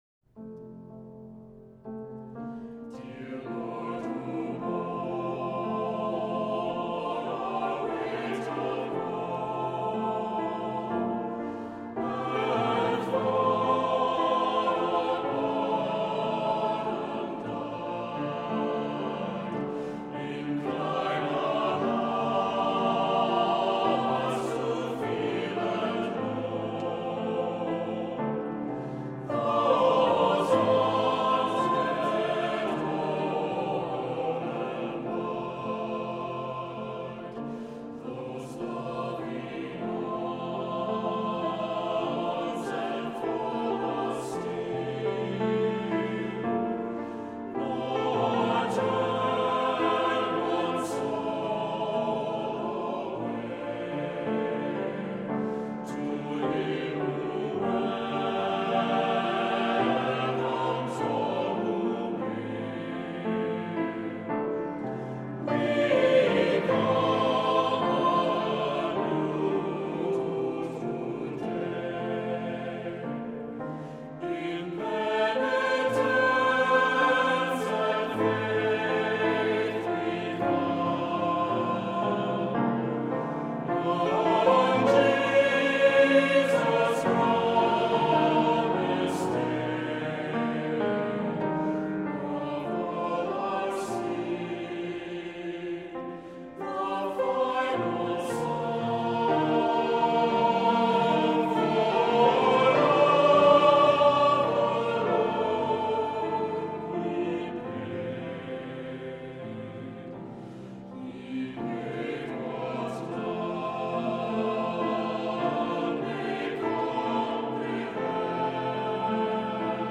Accompaniment:      Keyboard
Music Category:      Christian